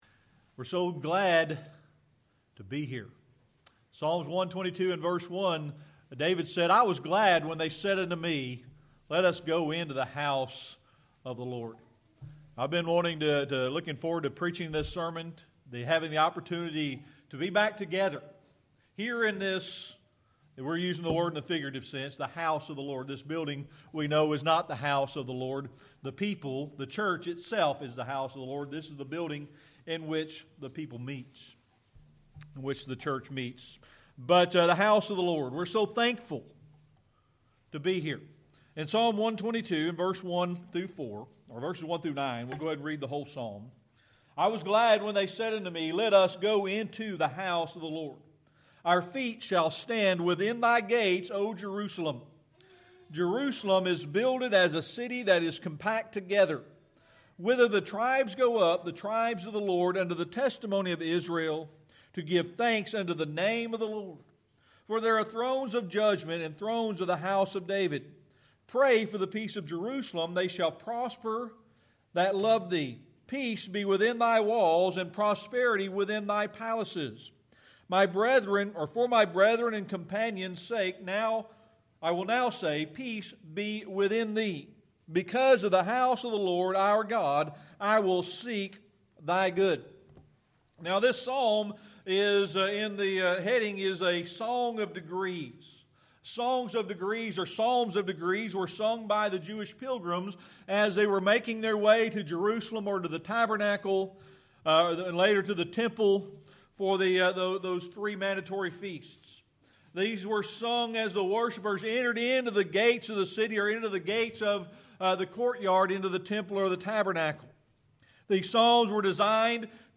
Series: Sermon Archives